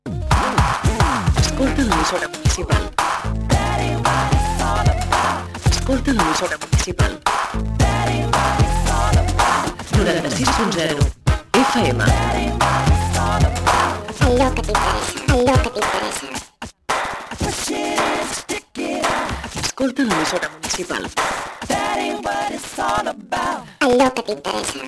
Identificació de l'emissora dins dels programes musicals